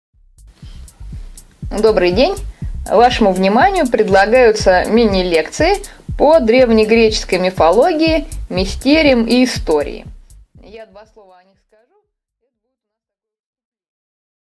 Аудиокнига 0. Мифы и мистерии. Вступление | Библиотека аудиокниг